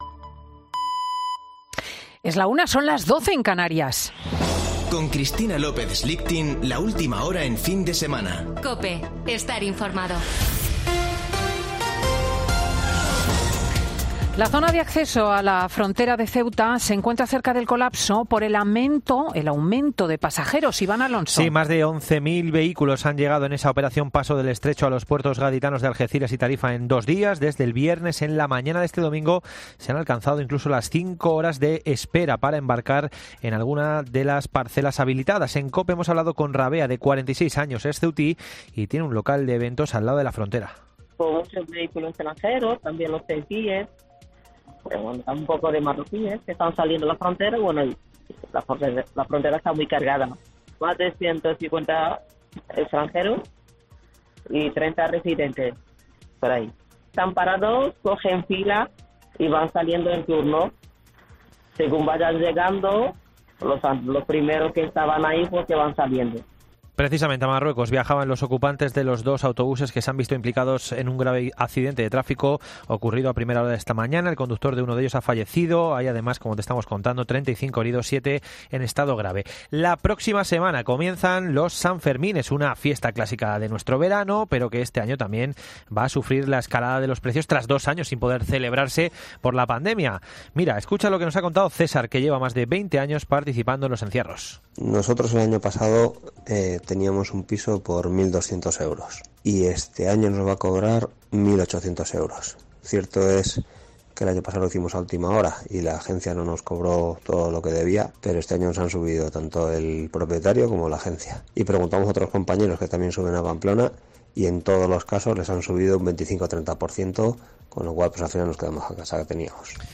Boletín de noticias de COPE del 3 de julio de 2022 a la 13.00 horas